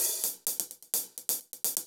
UHH_AcoustiHatA_128-03.wav